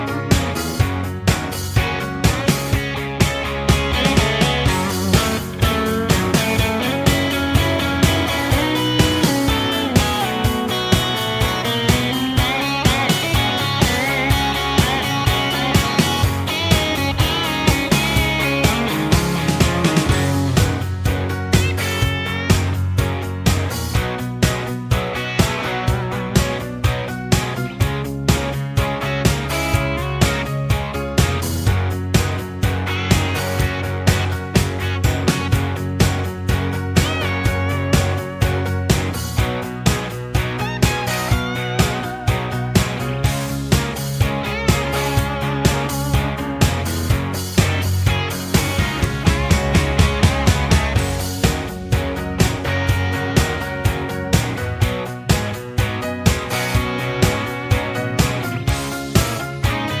no Backing Vocals or Sax Rock 'n' Roll 3:19 Buy £1.50